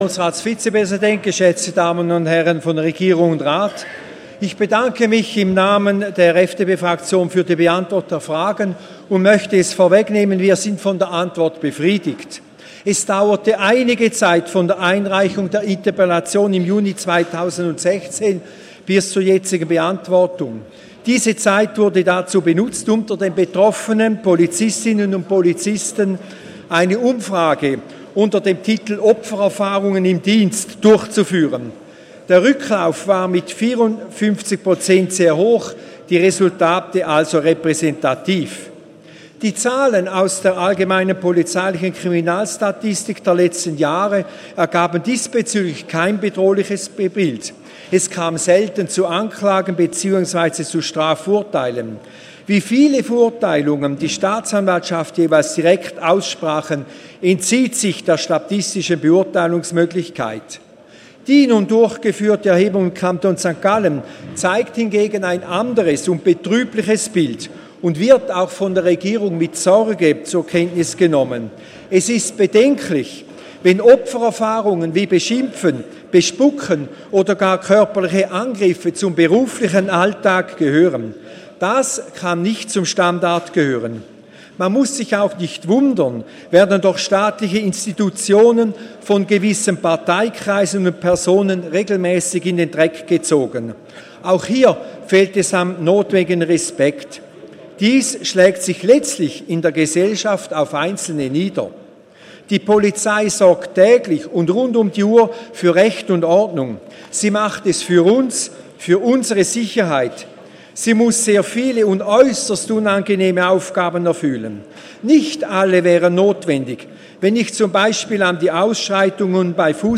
25.4.2017Wortmeldung
Session des Kantonsrates vom 24. und 25. April 2017